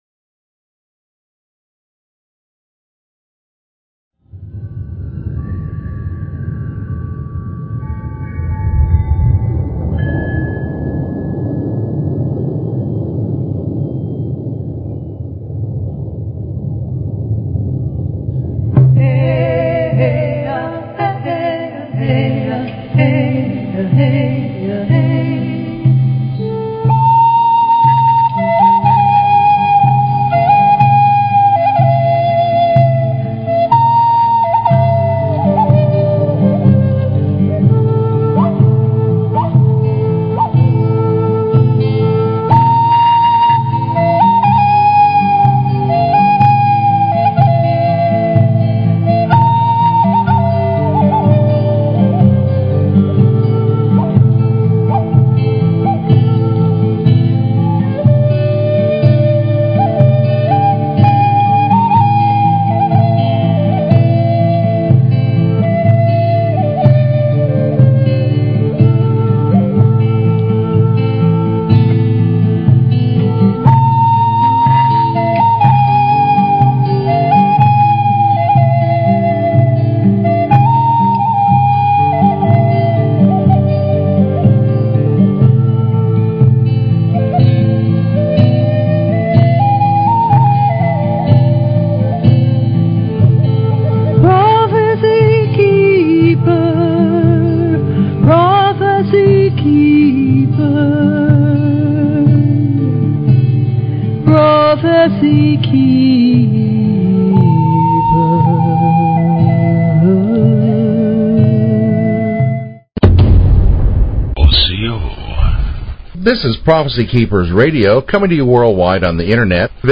Talk Show Episode, Audio Podcast, Prophecykeepers_Radio and Courtesy of BBS Radio on , show guests , about , categorized as